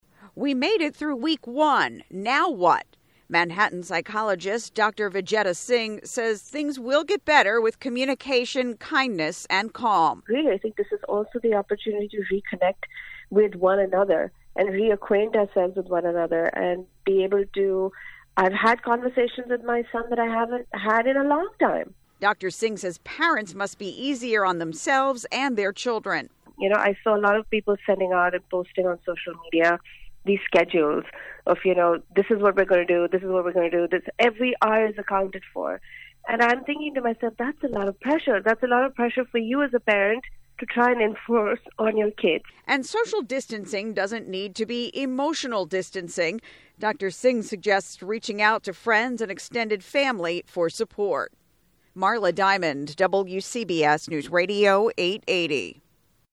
Radio & Podcast